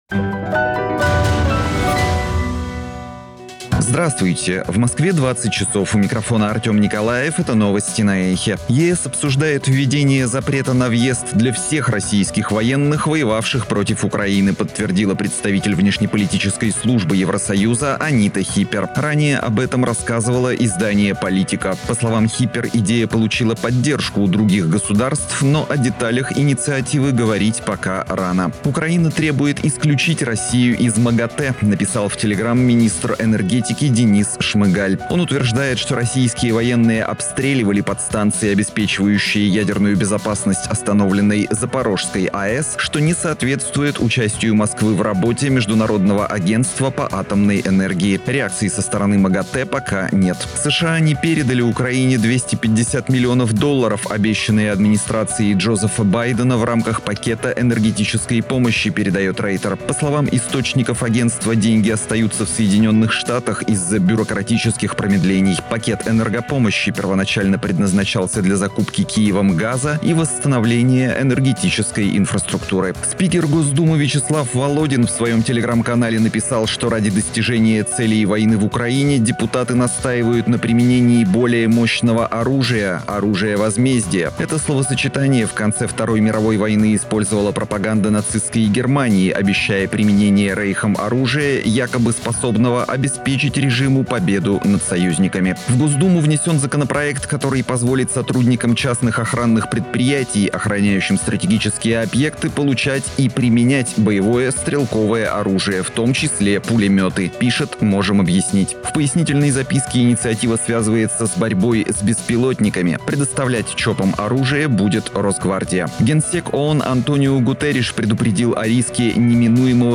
Слушайте свежий выпуск новостей «Эха»
Новости